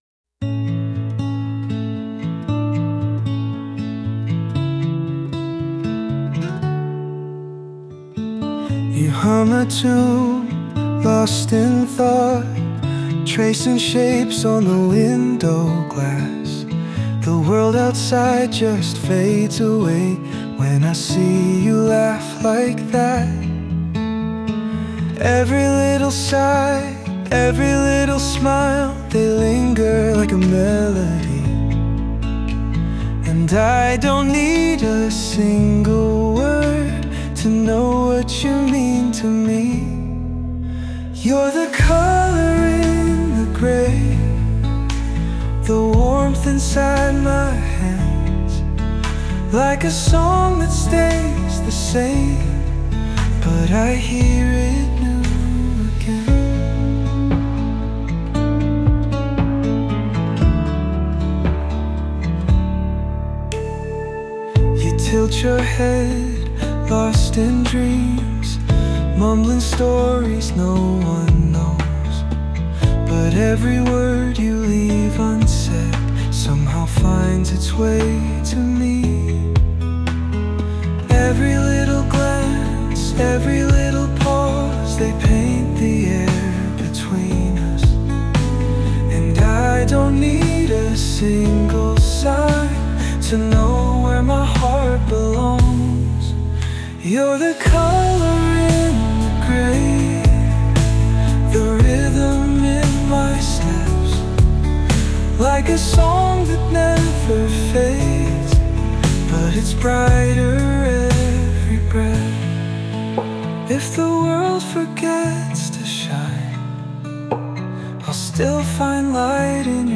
男性ボーカル（洋楽・英語）曲です。
優しい愛にあふれる男性ボーカルの洋楽をイメージして制作しました！